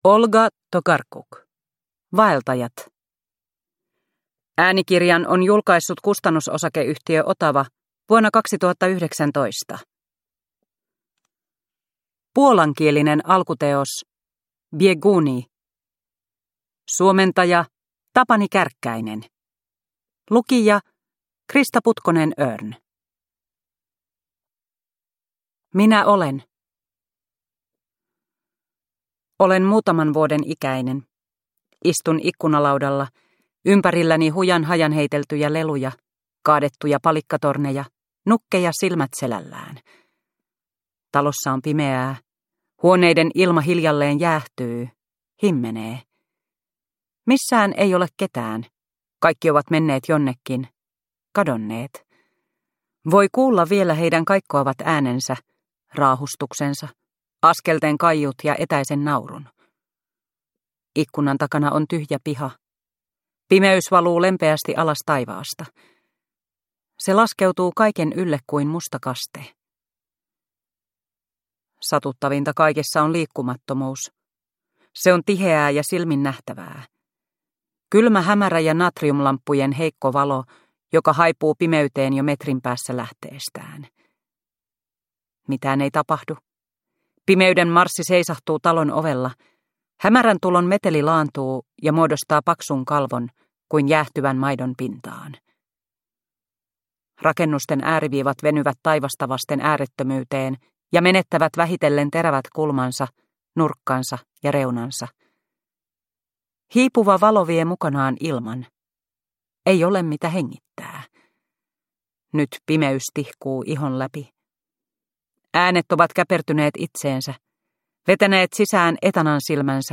Vaeltajat – Ljudbok – Laddas ner